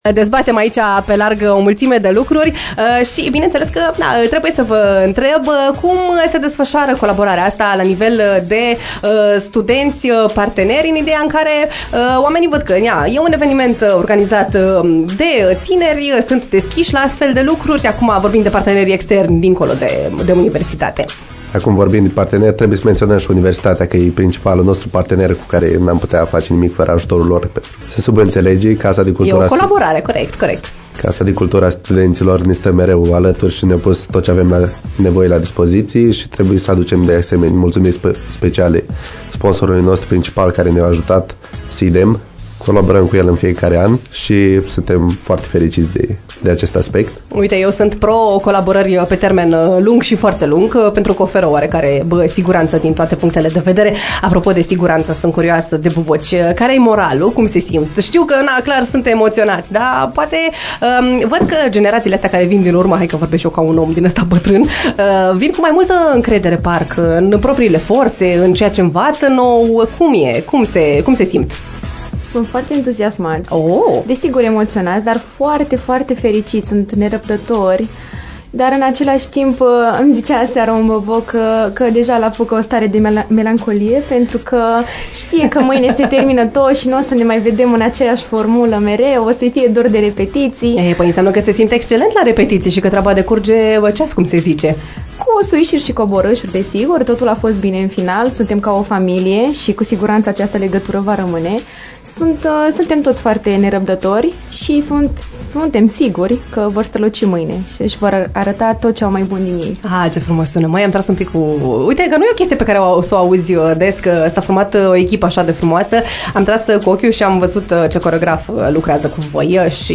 Live la AFTER MORNING: Balul Bobocilor USV – sold out!